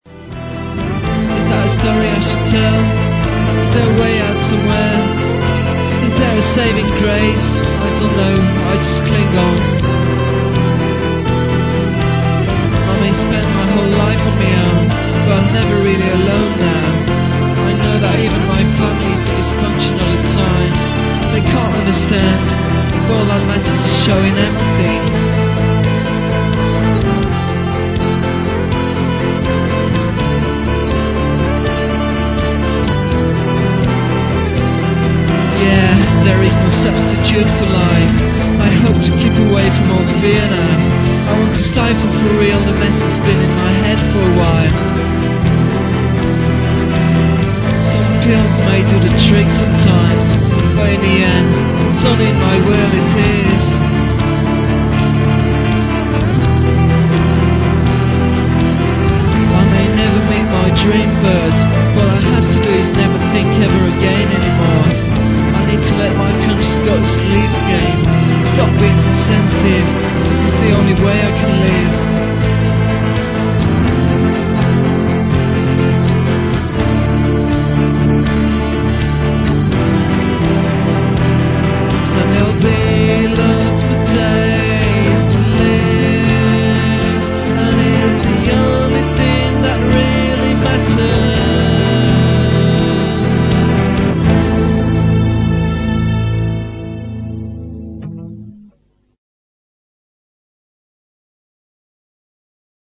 OK, let's be fair, I think the singing is atrocious, the music mostly rubbish, and the lyrics cringeworthy.